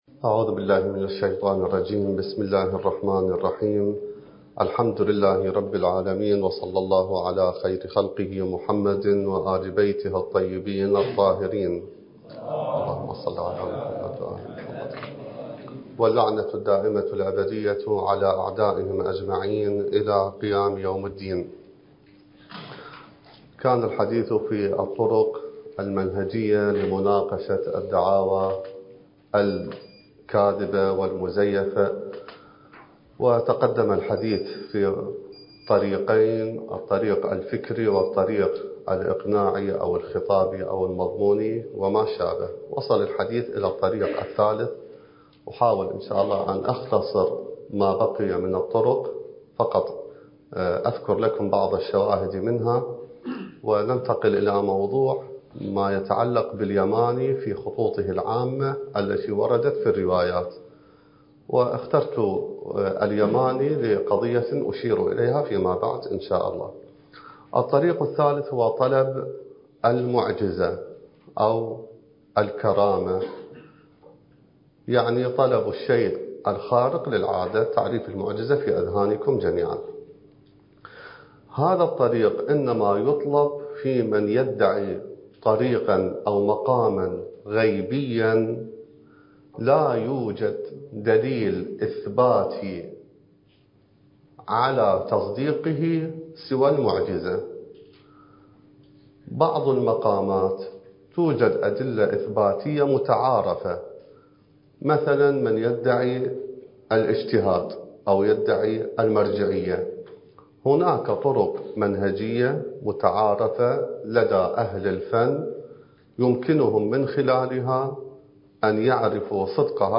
الدورة المهدوية الأولى المكثفة (المحاضرة الثانية عشر)